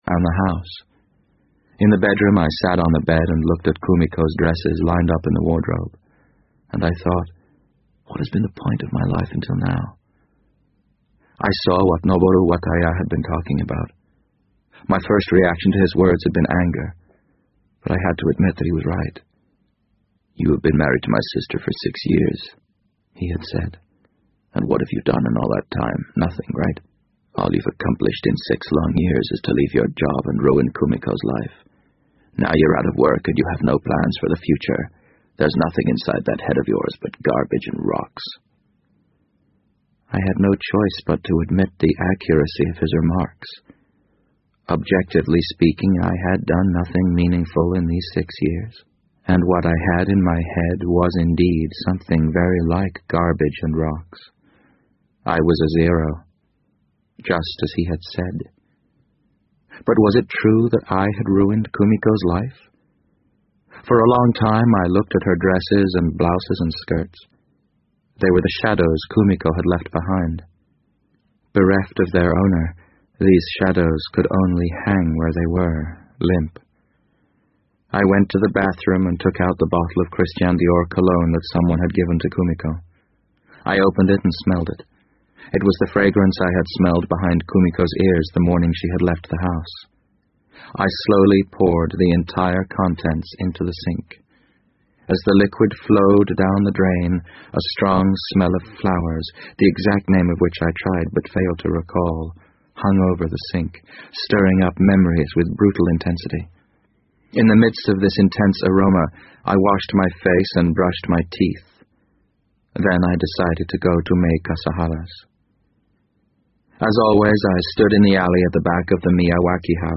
BBC英文广播剧在线听 The Wind Up Bird 006 - 4 听力文件下载—在线英语听力室